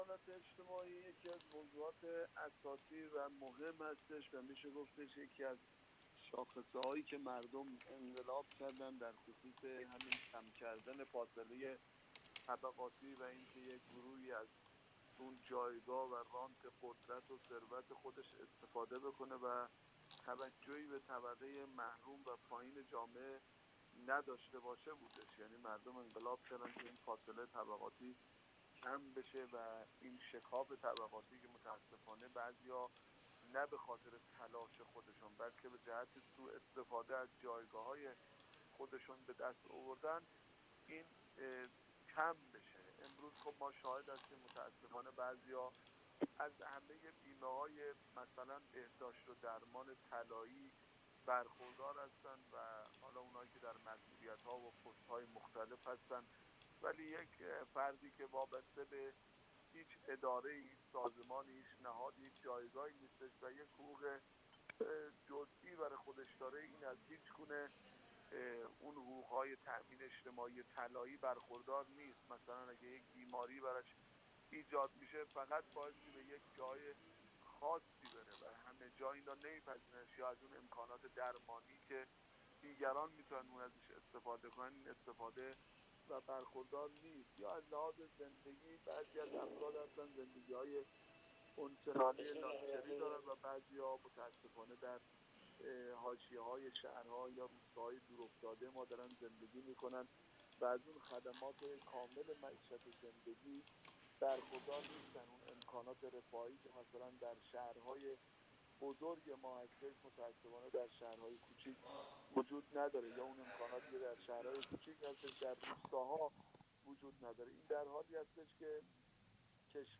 آصفری در گفت‌وگو با ایکنا بیان کرد: